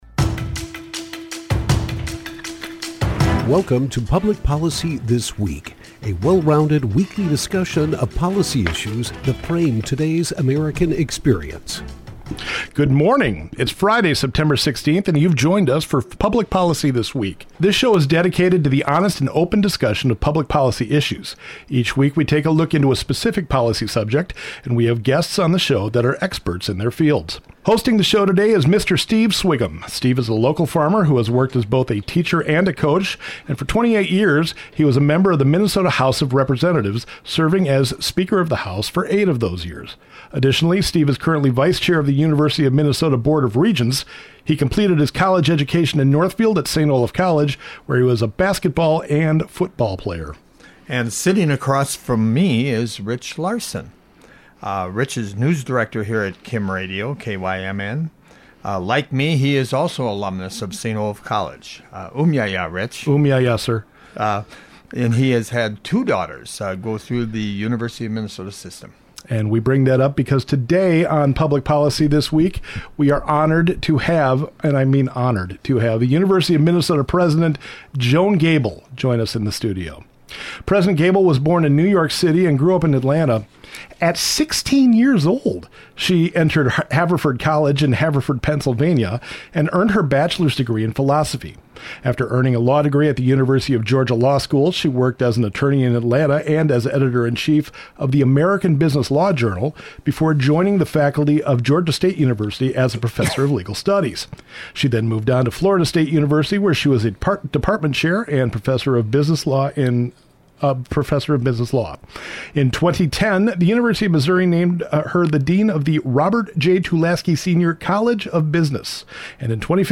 Public Policy This Week - A Conversation with President Joan Gabel of the University of Minnesota